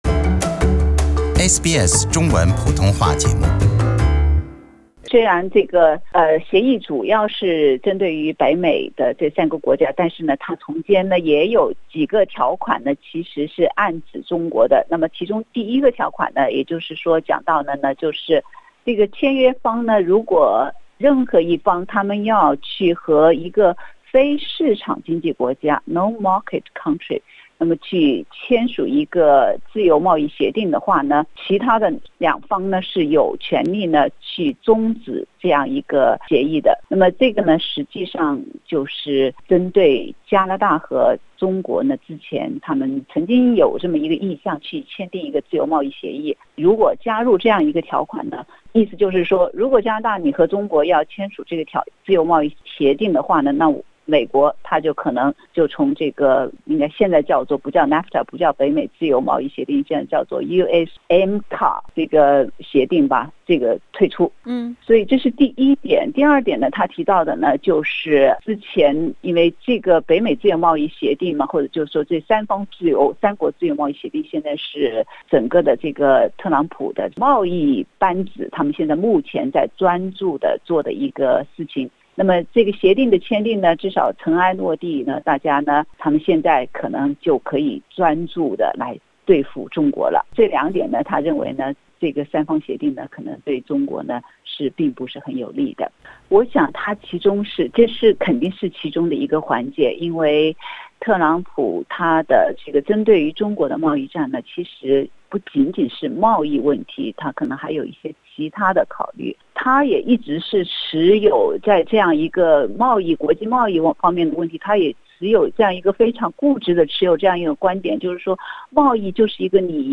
（本節目為嘉賓觀點，不代表本台立場。）